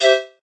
plop2.ogg